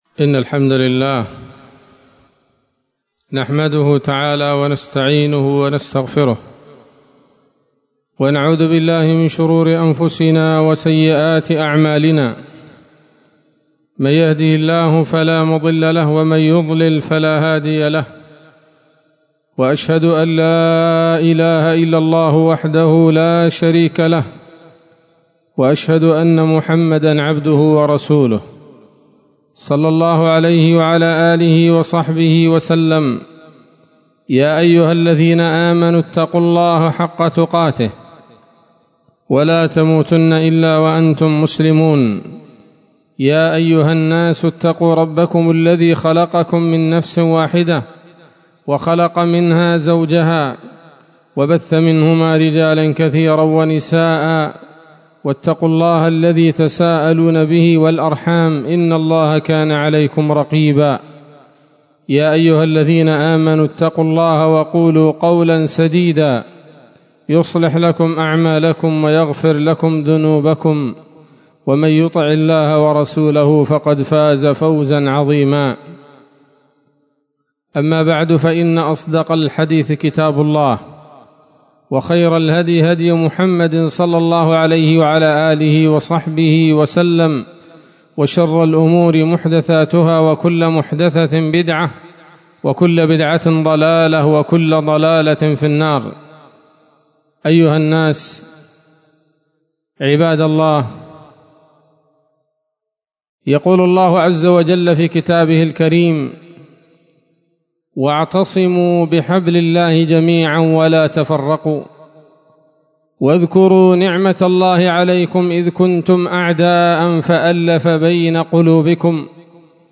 خطبة جمعة بعنوان: (( لا للتحزب أمة الإسلام )) 25 ربيع أول 1444 هـ، دار الحديث السلفية بصلاح الدين
لا-للتحزب-أمة-الإسلام-خطبة.mp3